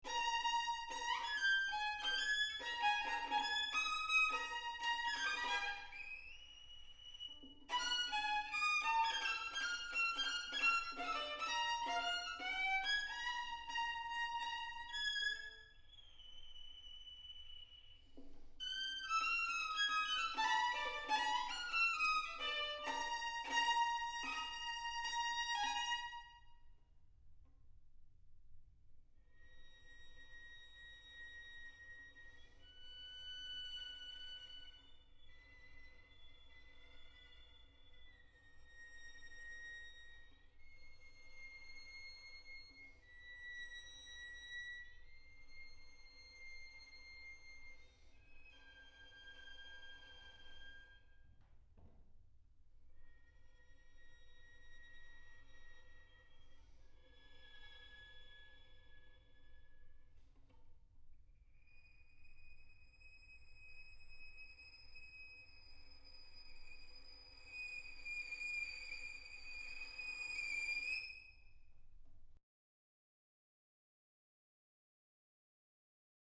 for violin solo (2011) [世界初演 / world première]
場所：杉並公会堂 小ホール